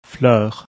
eu in fleur
no corresponding sound in English
FLEUR.MP3